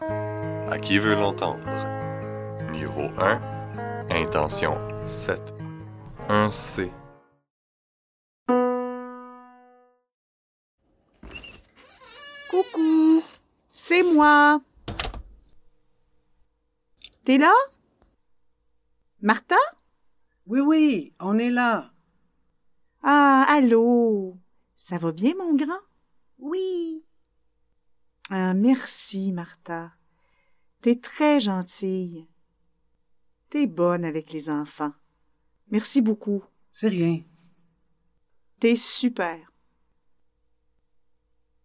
Indicatif présent : Associer [te] à tu es o